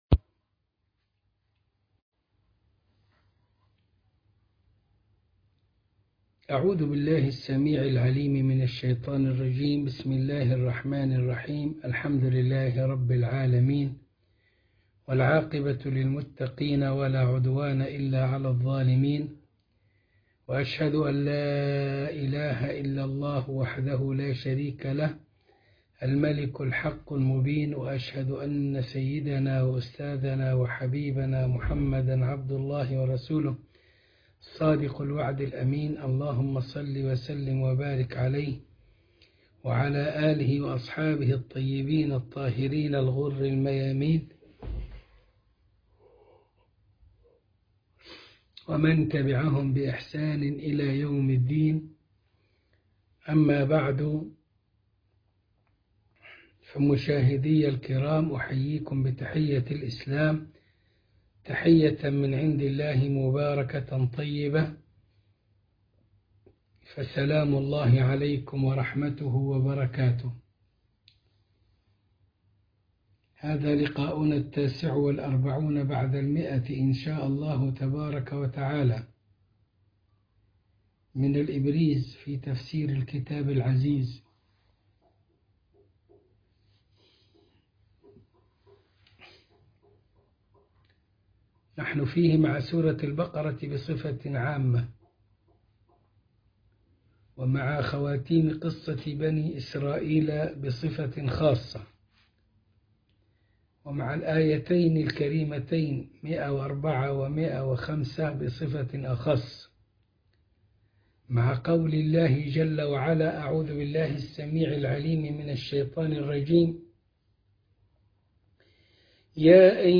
الدرس ١٤٩ من الإبريز في تفسير الكتاب العزيز سورة البقرة الآية ١٠٤ وما بعدها